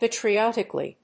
音節pa･tri･ot･i･cal･ly発音記号・読み方pèɪtriɑ́tɪk(ə)li|pæ̀triɔ́-